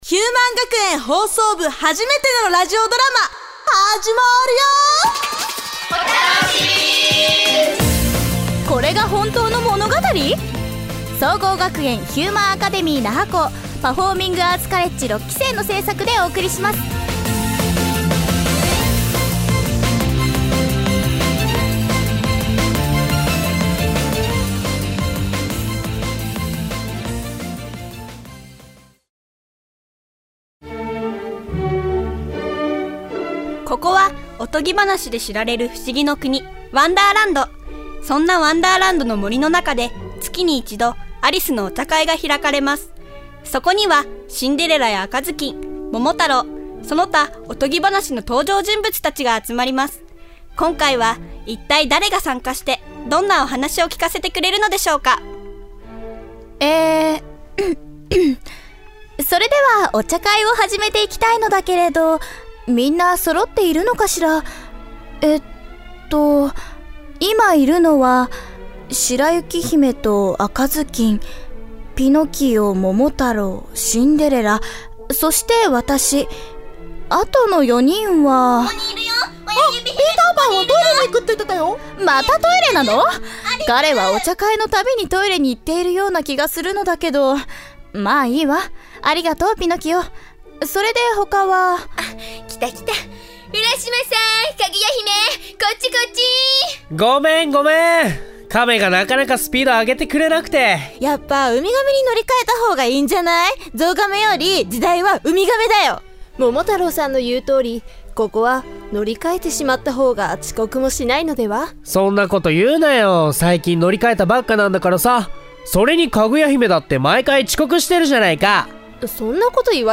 140210ヒューマン学園ラジオドラマ「これが本当の物語?!」/ヒューマンアカデミー那覇校声優専攻6期